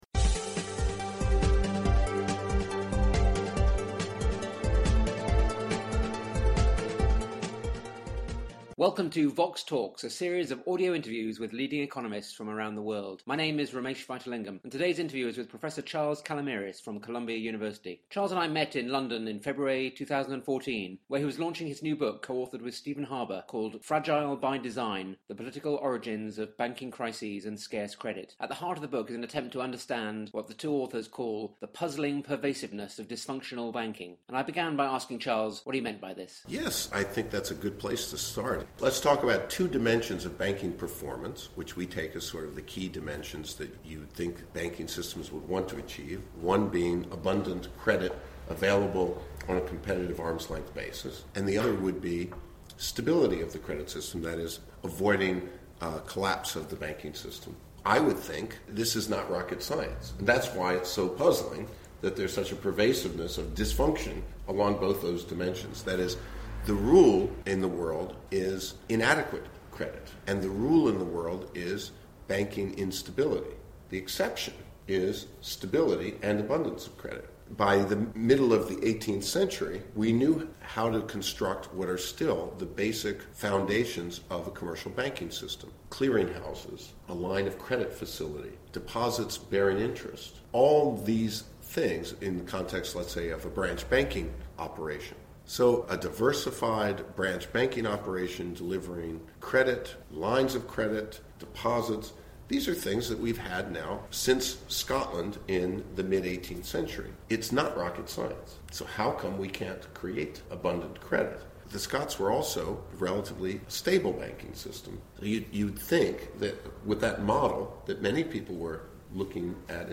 The interview was recorded in London in February 2014.